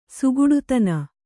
♪ suguḍutana